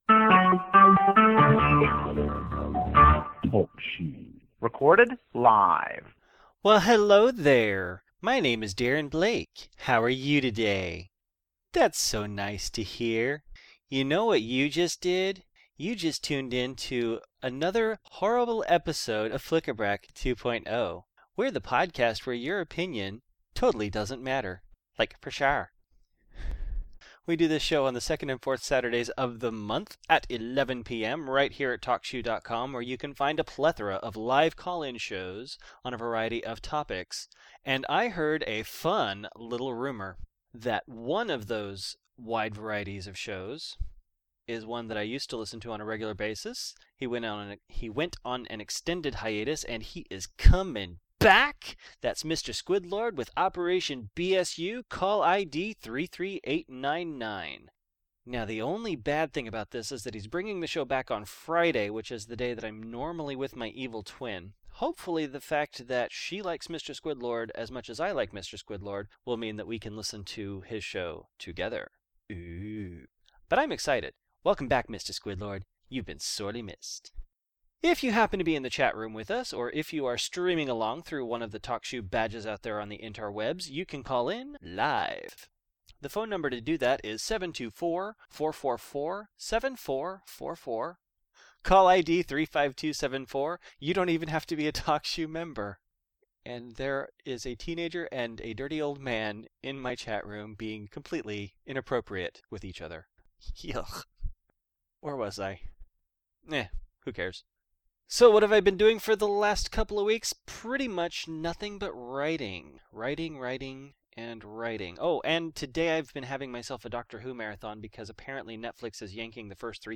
I got a replacement computer, but I had not yet recaptured all of the music I used for the theme and bumpers — so it sounds more like an old Unscripted.